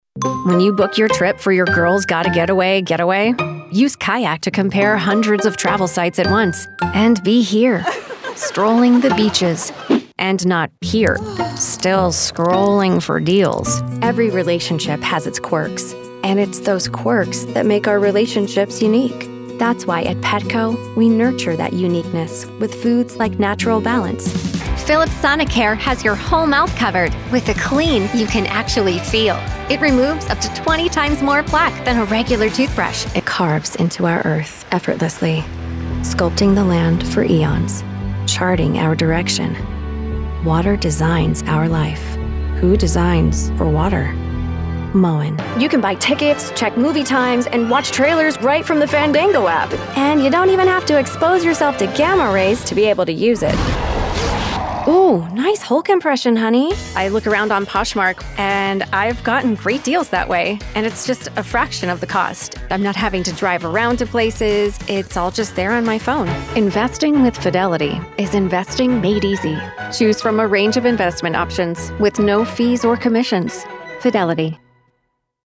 Commercial Demo 2025